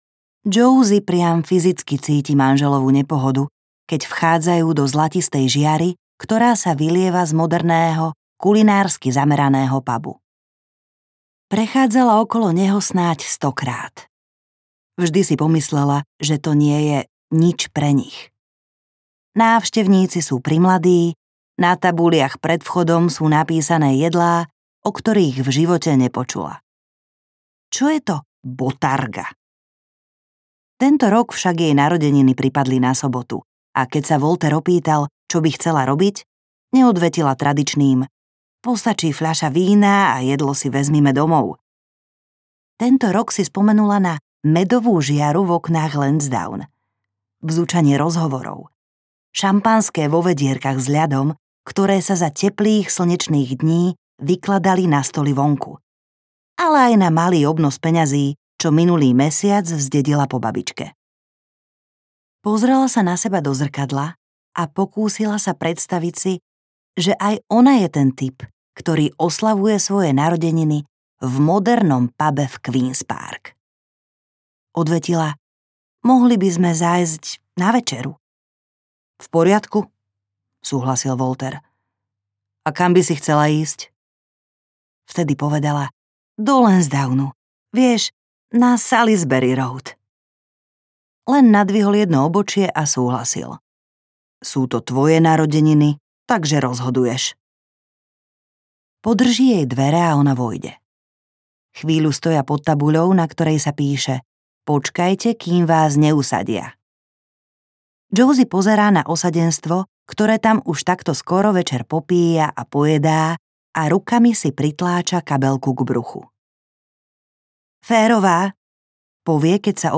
Never jej ani slovo audiokniha
Ukázka z knihy
• InterpretLujza Garajová Schrameková